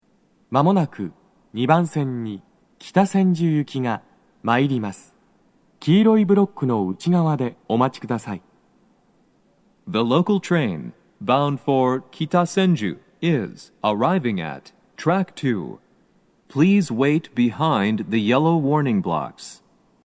–Â“®‚ÍA‚â‚â’x‚ß‚Å‚·B